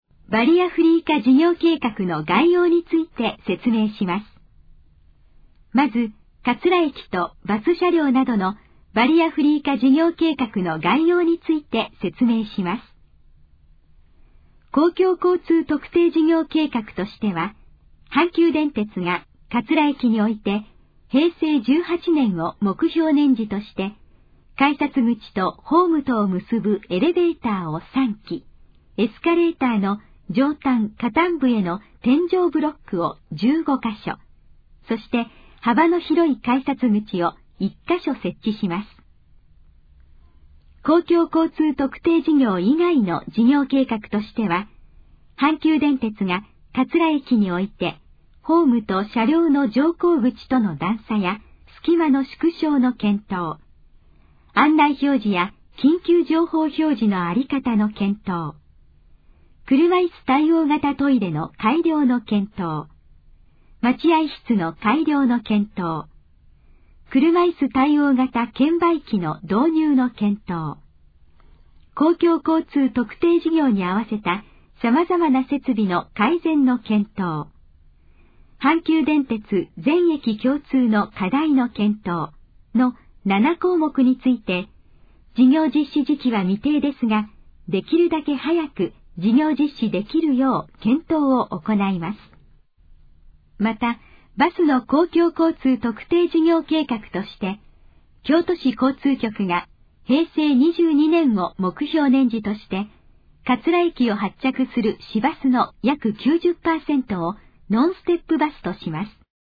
以下の項目の要約を音声で読み上げます。
ナレーション再生 約220KB